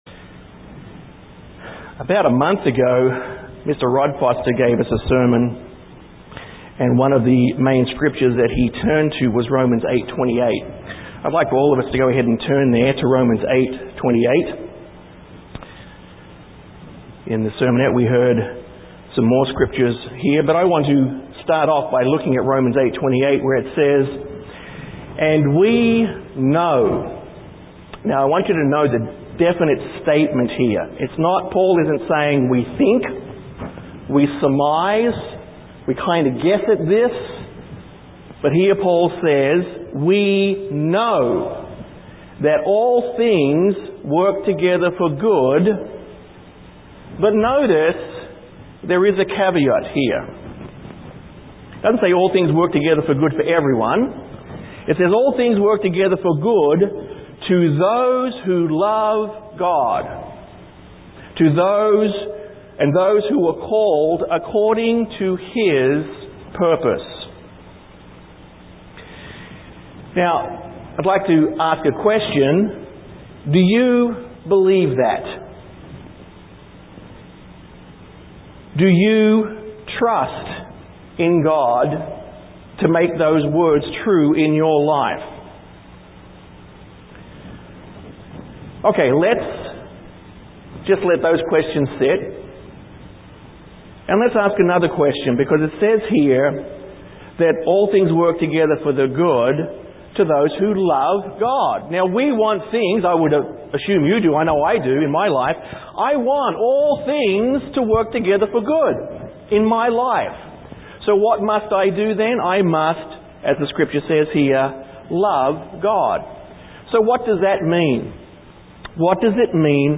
This sermon (first of two) discusses that need to "grow in faith". Faith is not a static concept or state but dynamic. This sermon begins to look at the trials (faith builders) of the father of the faithful, Abraham.